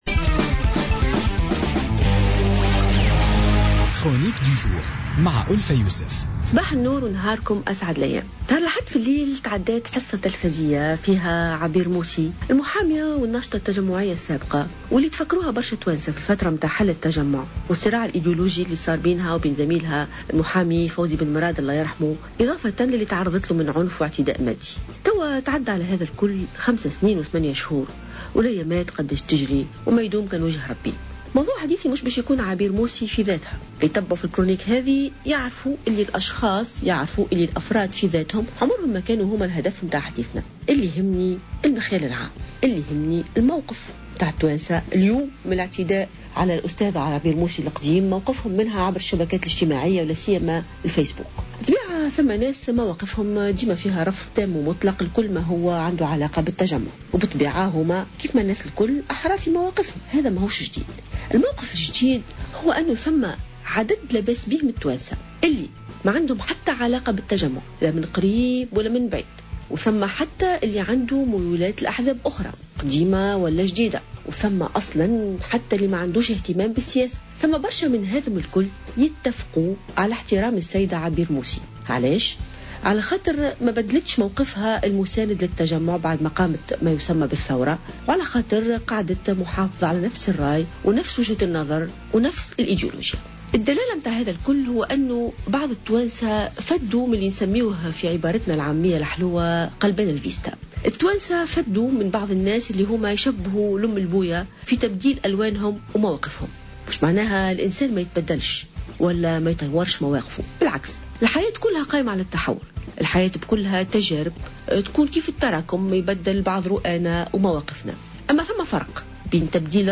انتقدت الجامعية ألفة يوسف في افتتاحية اليوم الأربعاء ظاهرة تغيير بعض الأشخاص لمواقفهم السياسية والاديولوجية.